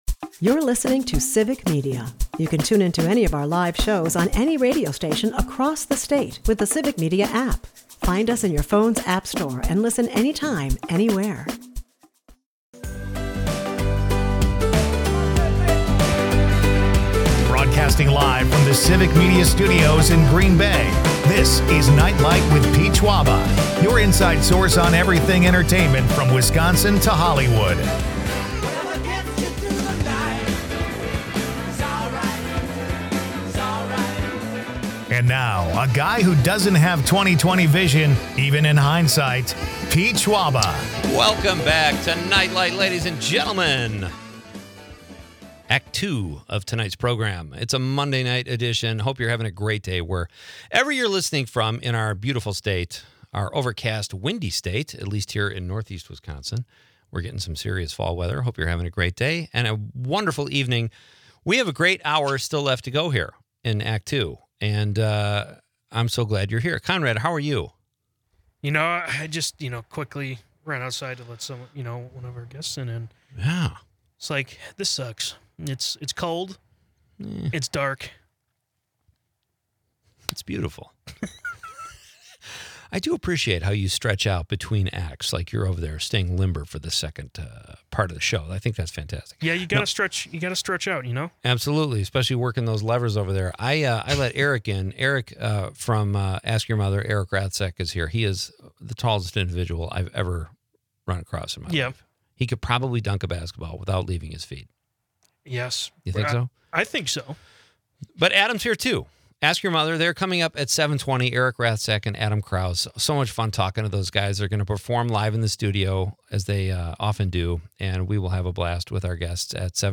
The episode dives deep into local Wisconsin culture, including tales from Door County's Fall Fest, and a spirited debate on favorite romantic songs, from Elvis to Lionel Richie. With humor and harmony, the show strikes a chord with both music and sports talk.